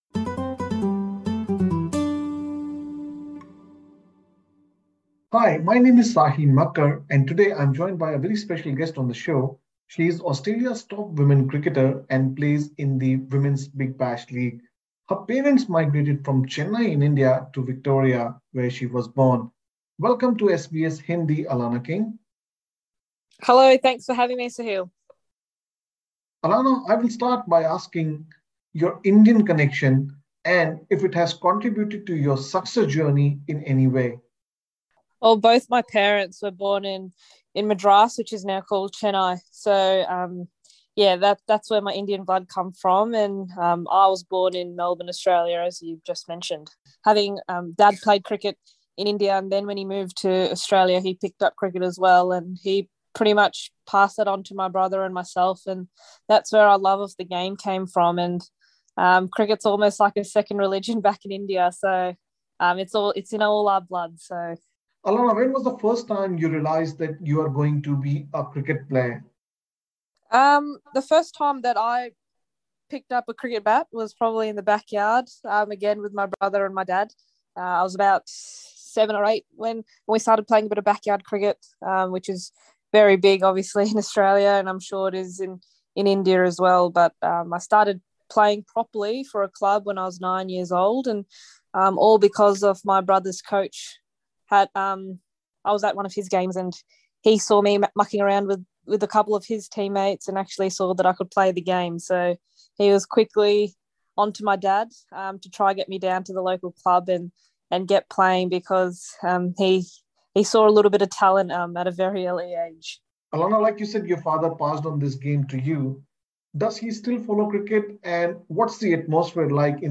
Alana King spoke to SBS Hindi about her Indian heritage and advice to aspiring players from the Indian subcontinent communities, including Sri Lanka, Pakistan, Nepal and Bangladesh. Alana said her love for cricket comes from her father, who played cricket in India.